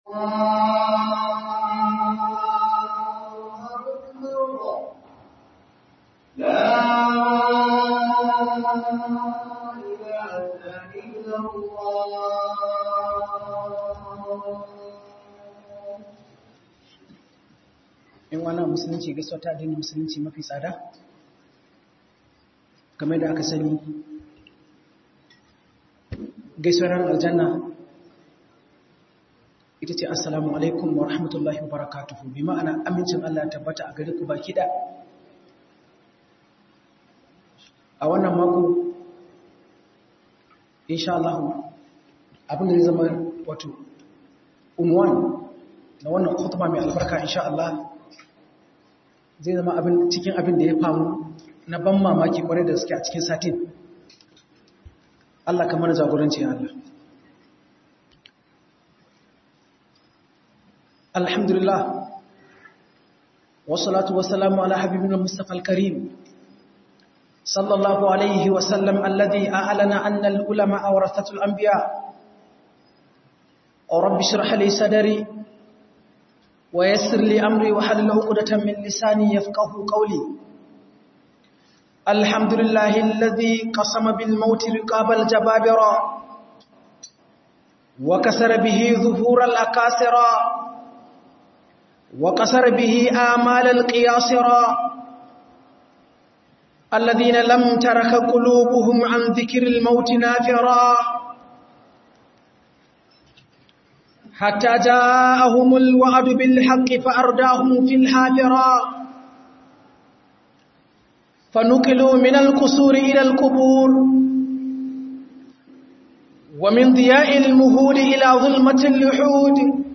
Tanadi a Mutuwa - HUDUBA